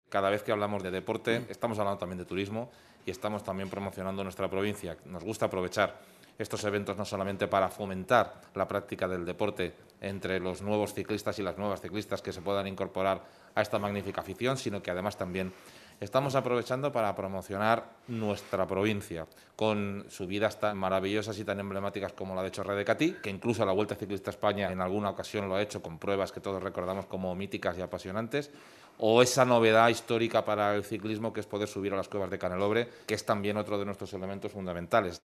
Vuelta-Ciclista-a-la-Provincia-CORTE-CARLOS-MAZON.mp3